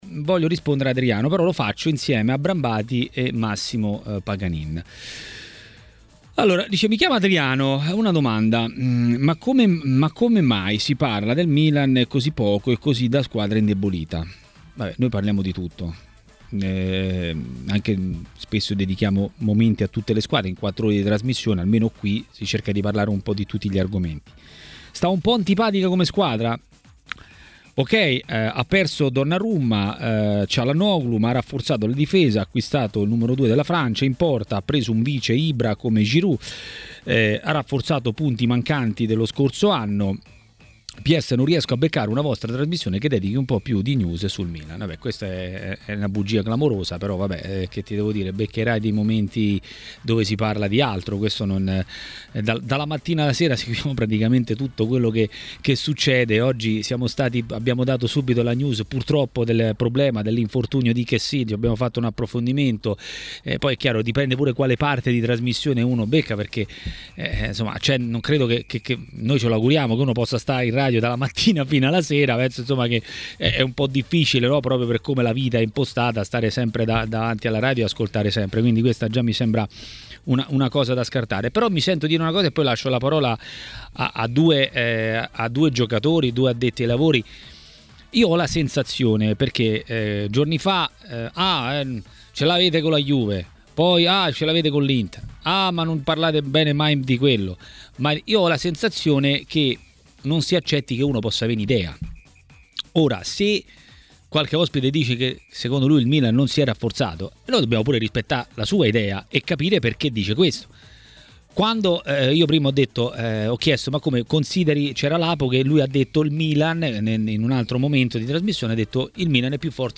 è intervenuto ai microfoni di TMW Radio durante 'Maracanà'. L’Inter è ancora competitiva?